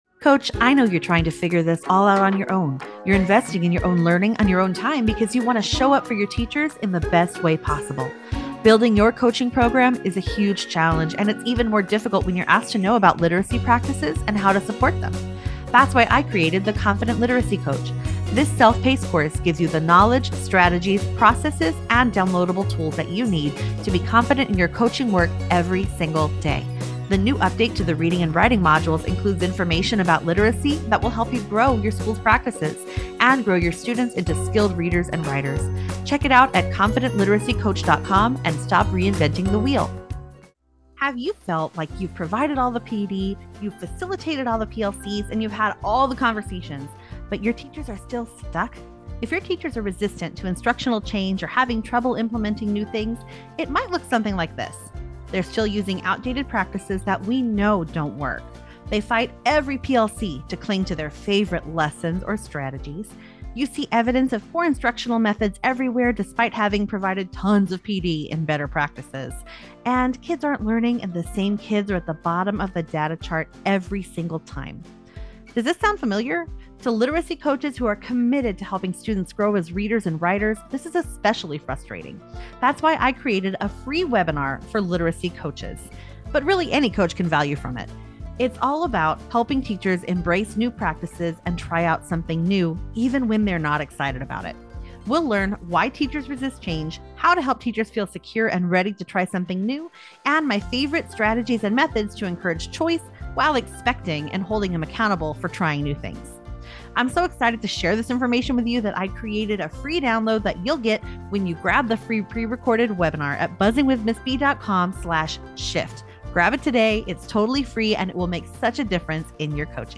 You don’t want to miss this informative and engaging conversation about creating a culture of learning and the important role principals play.